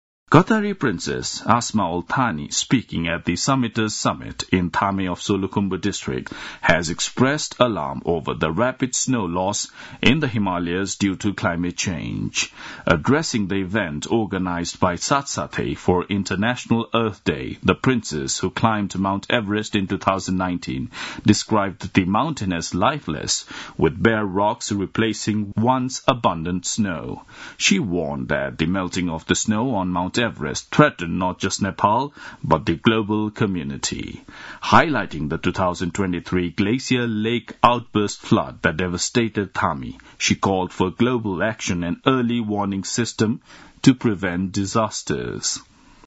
Qatari princess warns of Himalayan snow loss at Everest summit (audio news)